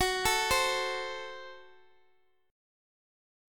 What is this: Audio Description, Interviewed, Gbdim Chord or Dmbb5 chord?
Gbdim Chord